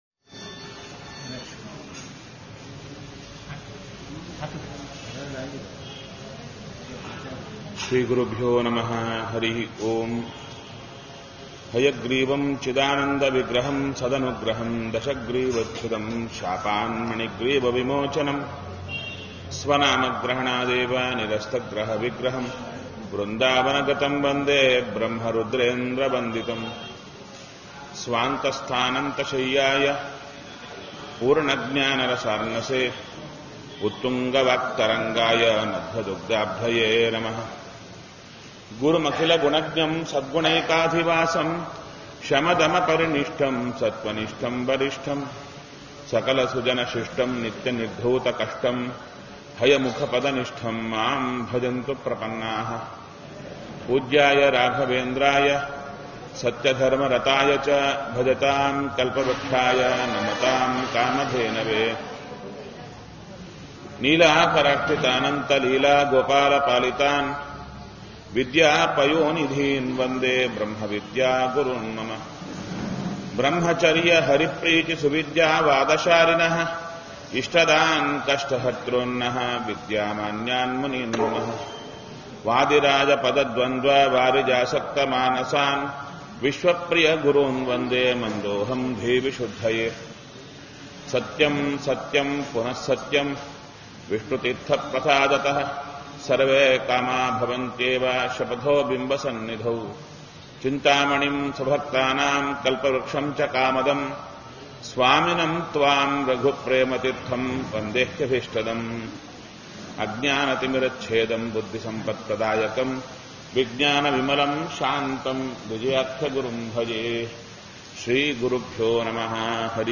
Go Home Pravachana Bhagavatgeete Geete At Srirangam Geete At Srirangam 4.M4a Geete At Srirangam 4.M4a